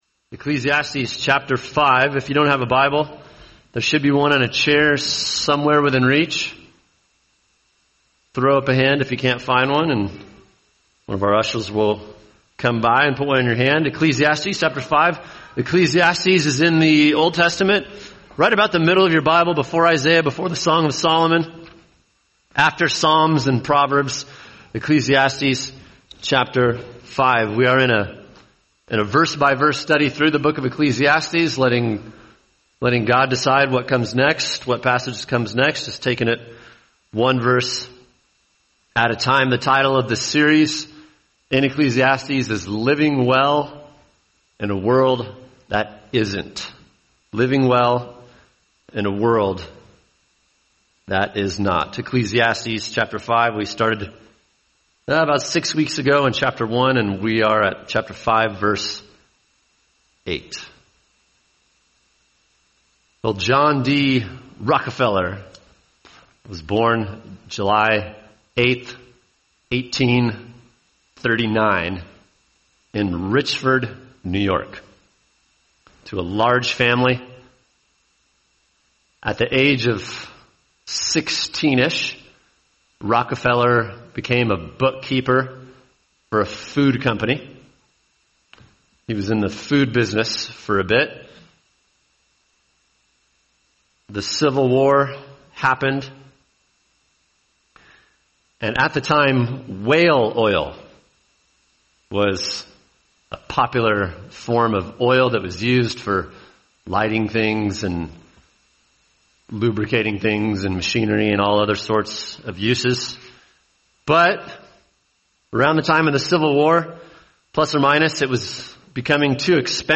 [sermon] Ecclesiastes 5:8-6:9 Living Well In A World That Isn’t – Money Problems | Cornerstone Church - Jackson Hole